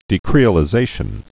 (dē-krēə-lĭ-zāshən)